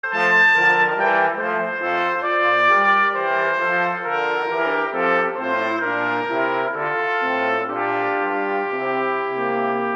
Gattung: für Saxophonquartett
Besetzung: Instrumentalnoten für Saxophon